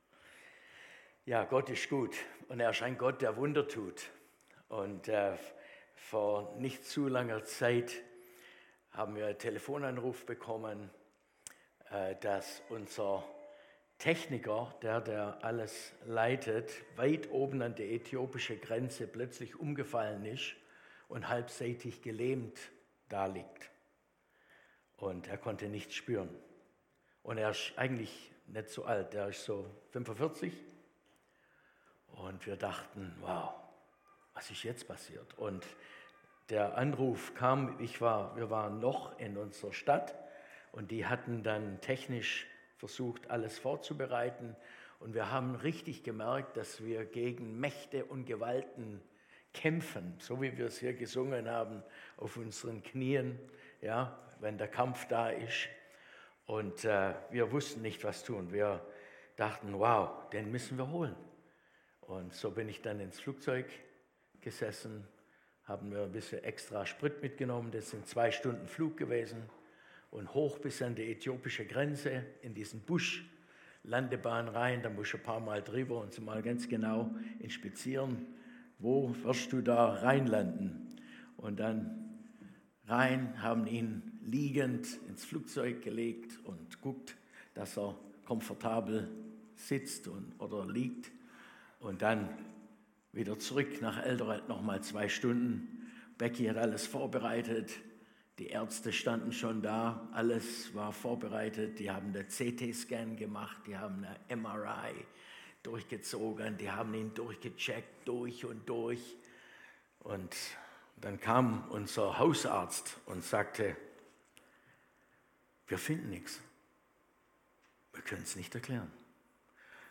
Go In Gottesdienst am 20.07.2025